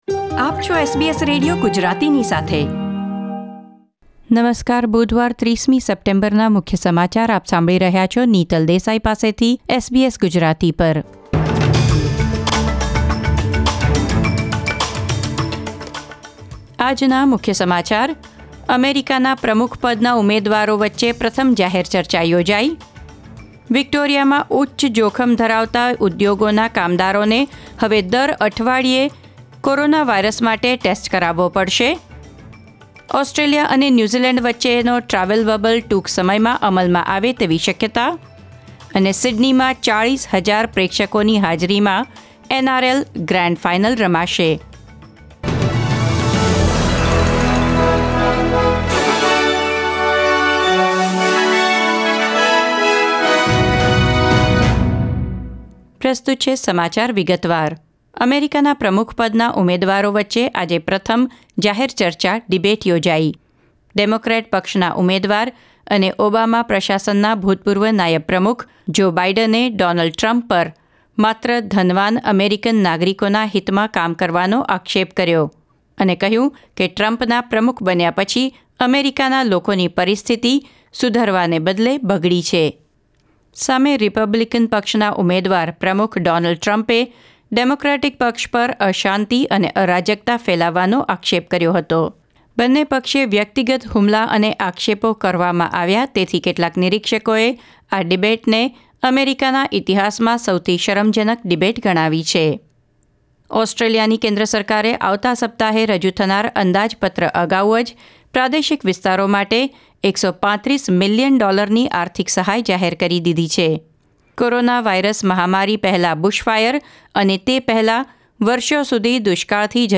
SBS Gujarati News Bulletin 30 September 2020